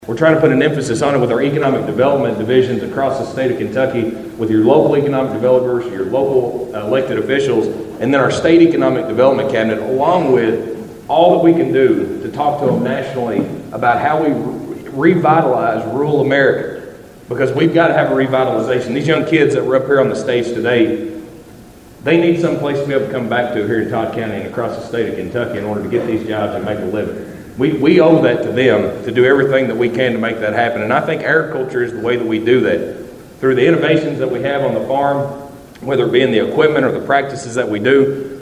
Shell was the featured speaker for the Todd County Agriculture Appreciation Breakfast at the Elkton Baptist Church Fellowship Hall Friday morning.